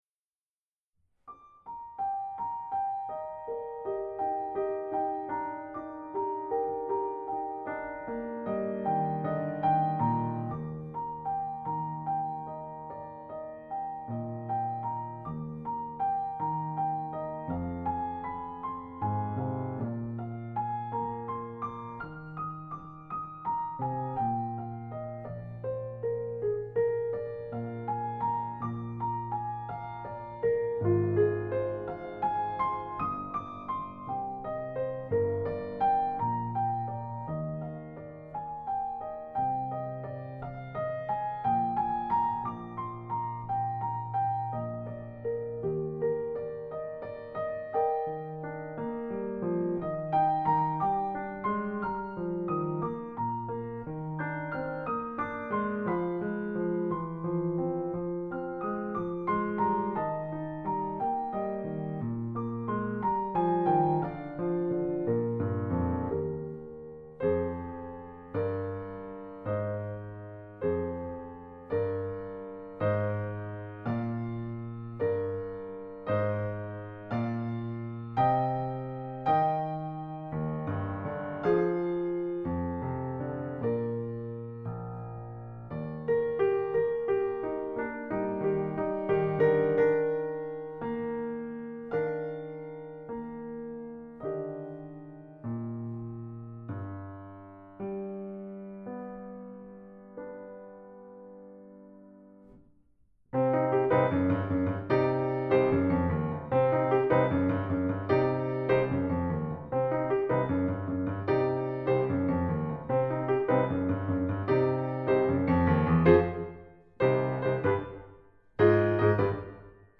They use a live pianist recording.
Audio: Piano part alone